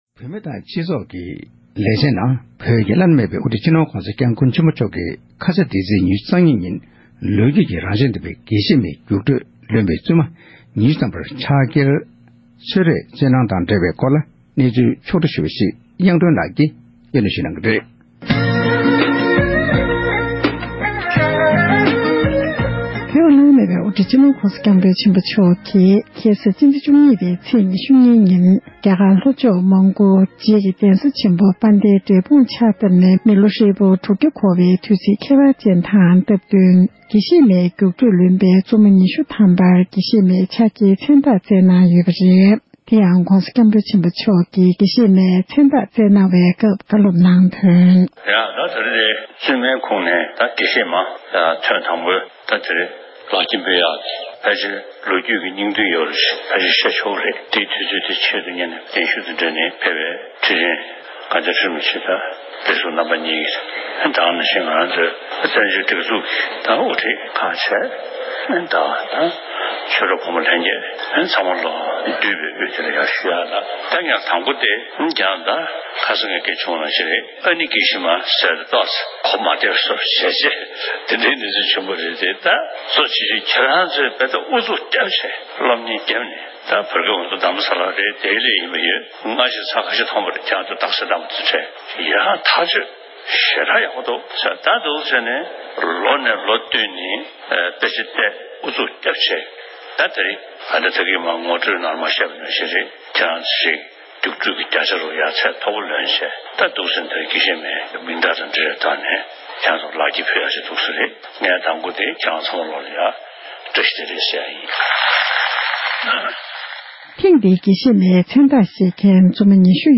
དགེ་བཤེས་མའི་བསླབ་པ་མཐར་སོན་གྱི་ཕྱག་འཁྱེར་མཛད་སྒོ་ས་གནས་གསར་འགོད་པས་གནས་ཚུལ་ཞིབ་ཕྲ་བཏང་བར་གསན་རོགས།
སྒྲ་ལྡན་གསར་འགྱུར།